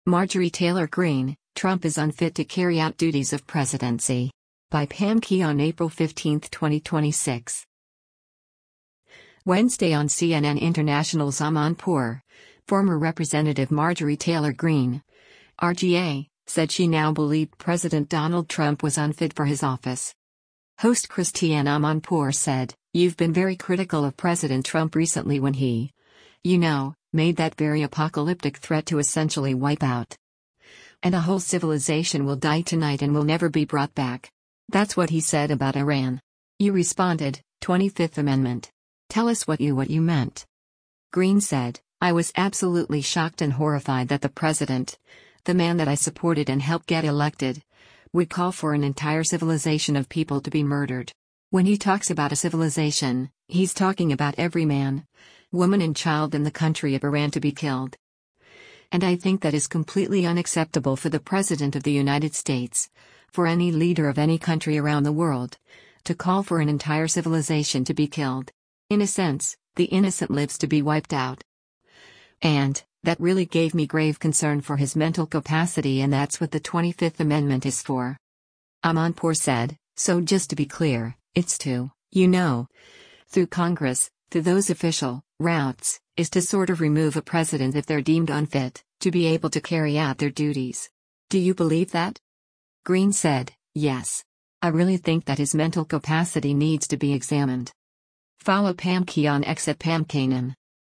Wednesday on CNN International’s “Amanpour,” former Rep. Marjorie Taylor Greene (R-GA) said she “now” believed President Donald Trump was unfit for his office.